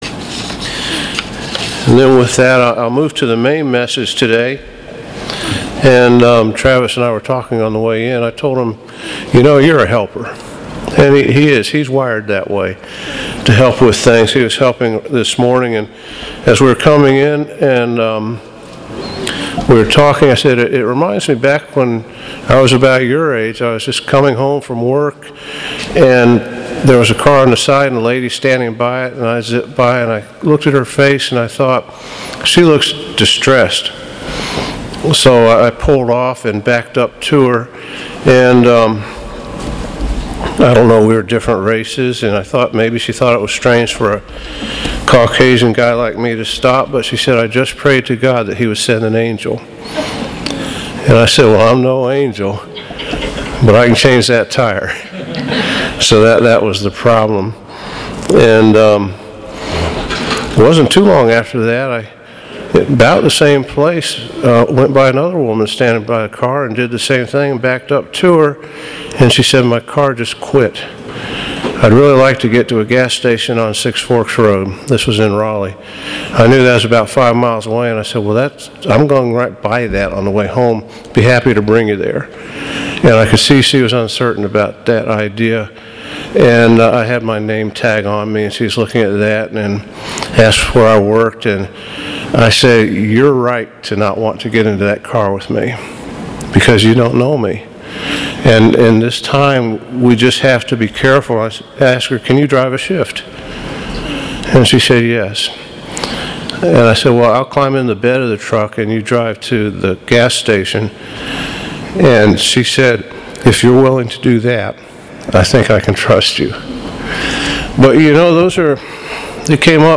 Sermons
Given in Buford, GA